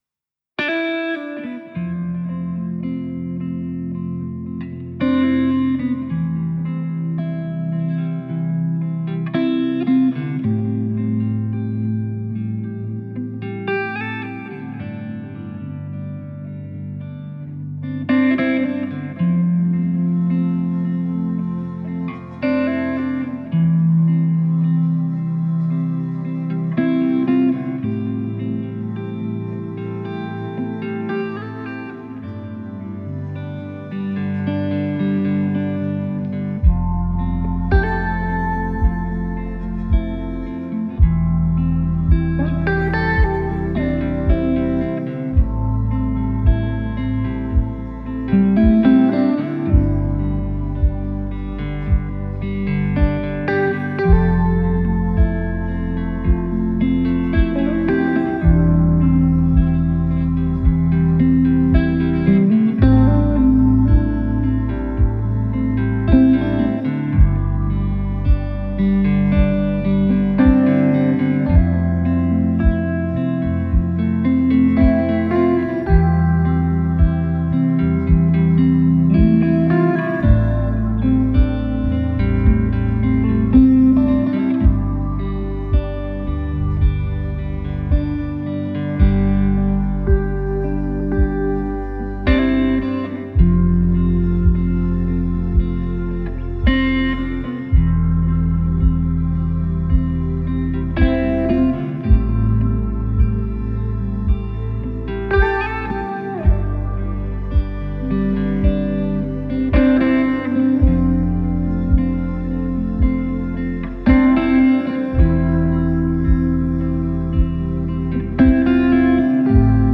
공부와 깊은 집중을 위한 일렉트로 릴랙싱 기타